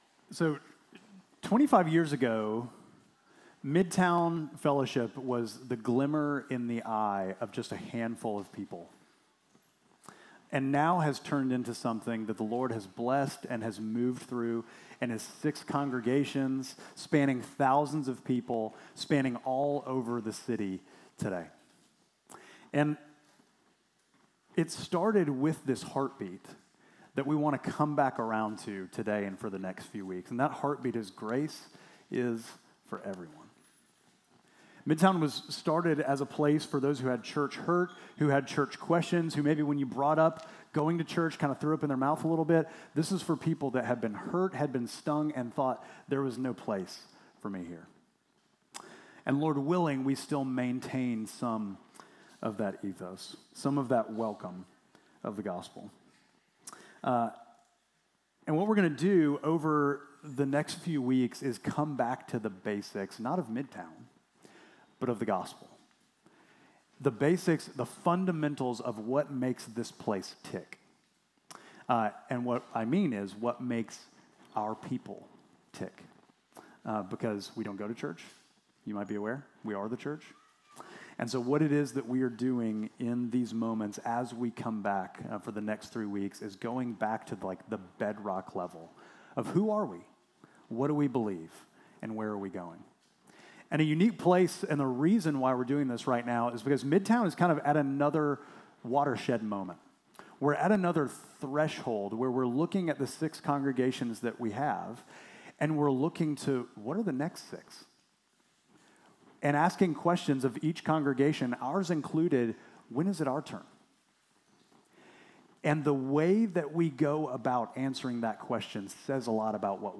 Midtown Fellowship Crieve Hall Sermons Made to Let In Aug 17 2025 | 00:38:12 1x 00:00 / 00:38:12 Subscribe Share Apple Podcasts Spotify Overcast RSS Feed Share Link Embed